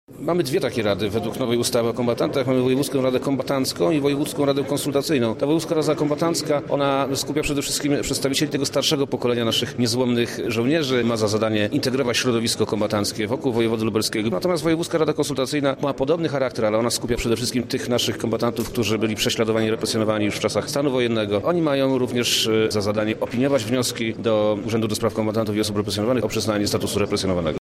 O tym kto znajduje się w radach i o zakresie ich działań mówi wojewoda lubelski Przemysław Czarnek